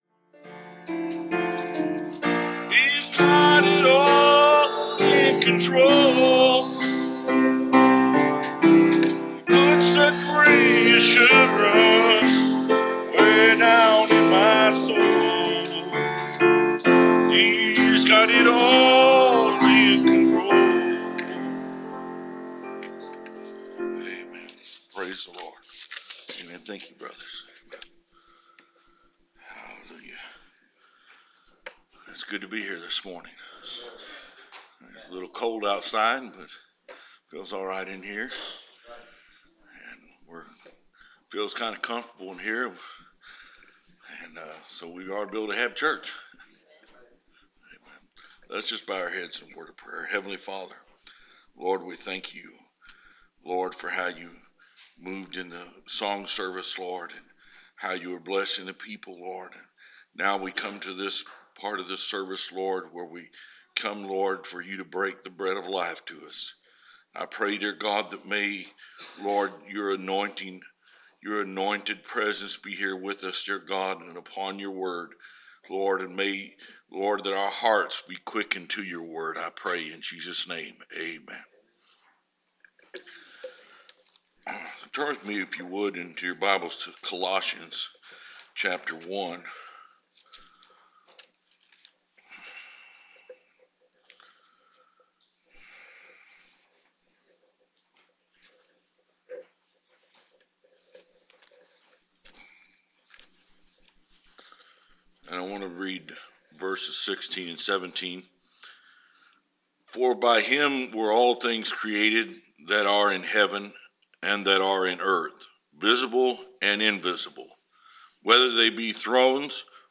Preached December 11, 2016